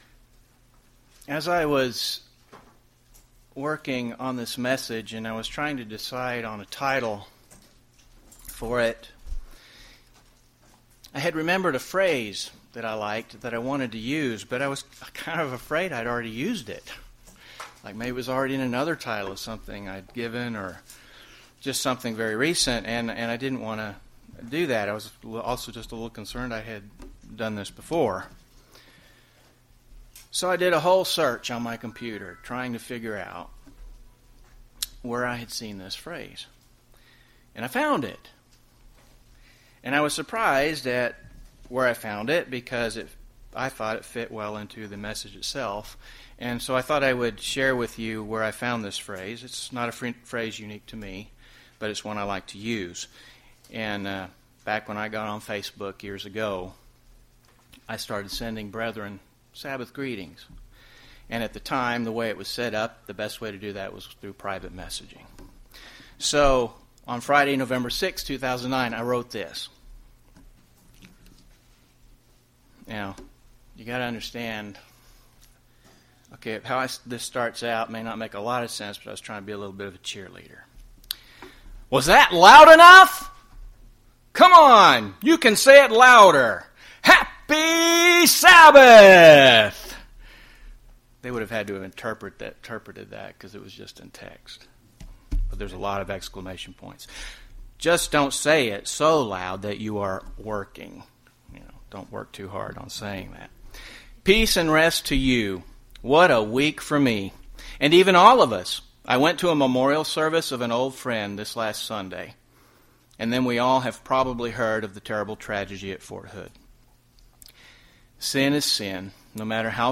UCG Sermon Unleavened Bread Holy Day Services commitment to God God's righteousness Notes PRESENTER'S NOTES Peace and rest to YOU!